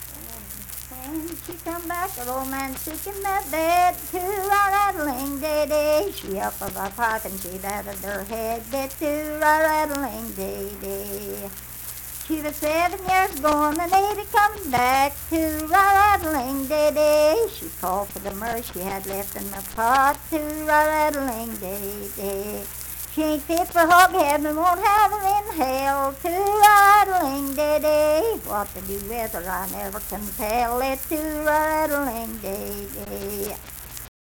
Unaccompanied vocal music performance
Verse-refrain 3(4w/R).
Voice (sung)